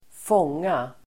Uttal: [²f'ång:a]